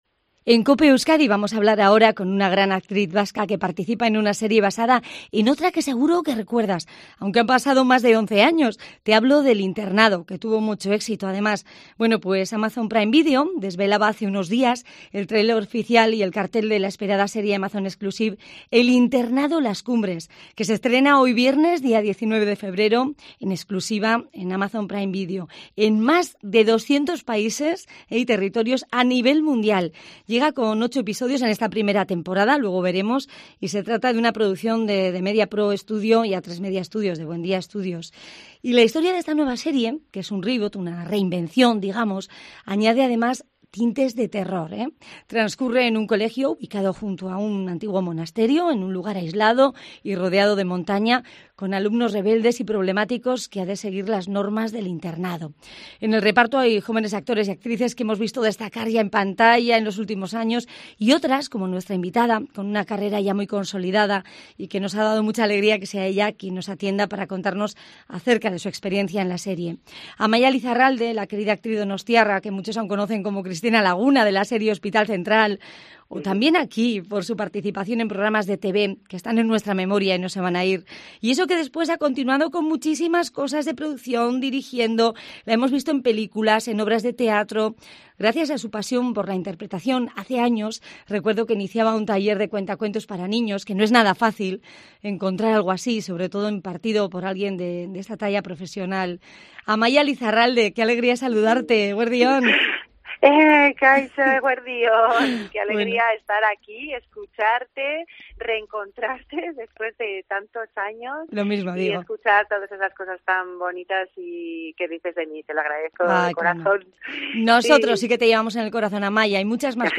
00:00 Volumen Descargar AMAIA LIZARRALDE Redacción digital Madrid - Publicado el 19 feb 2021, 10:31 2 min lectura Facebook Twitter Whatsapp Telegram Enviar por email Copiar enlace En COPE Euskadi hoy hemos tenido el placer de compartir un ratito con una querida gran actriz vasca que participa en la nueva serie 'El Internado' como actriz y también aportando su trabajo como coach para otros actores: AMAIA LIZARRALDE.
En la entrevista nos ha contado acerca de estos últimos años en los que ha vivido en E.E.U.U. antes de volver a Donosti.